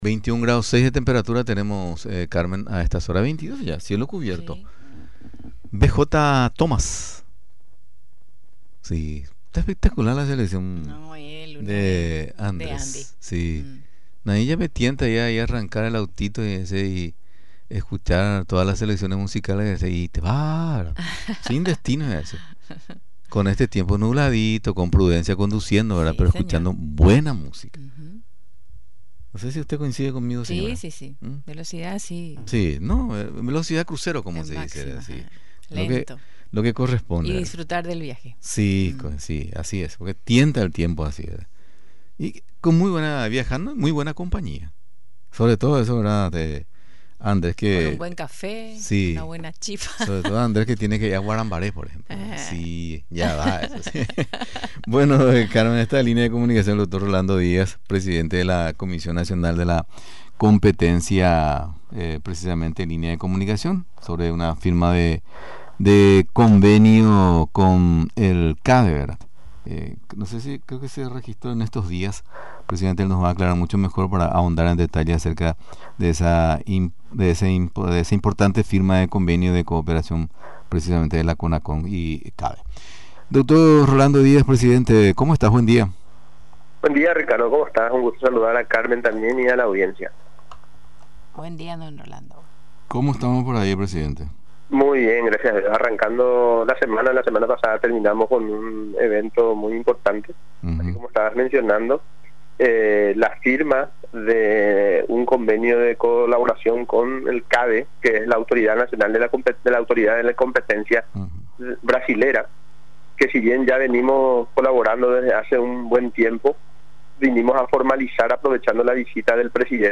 Asunción, Radio Nacional.-Con el objetivo de realizar una cooperación y asistencia técnica, Paraguay y Brasil, suscribieron un convenio de cooperación entre la Comisión Nacional de la Competencia y el CADE, destacó en el programa Paraguay Puede, el titular de la CONACOM, doctor Rolando Díaz.